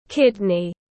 Quả cật tiếng anh gọi là kidney, phiên âm tiếng anh đọc là /ˈkɪdni/